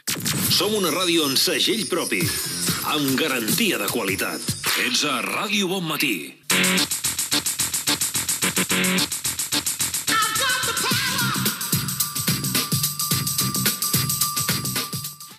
Indicatiu de l'emissora i tema musical